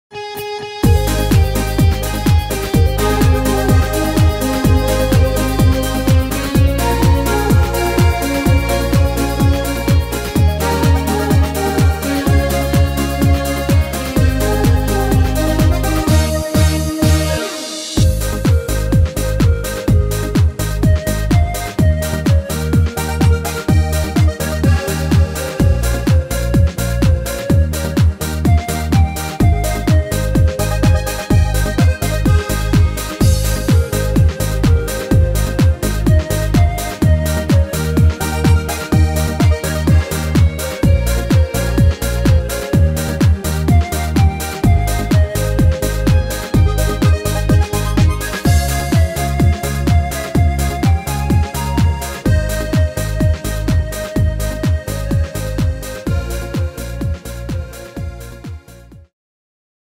Tempo: 126 / Tonart: Ab-Dur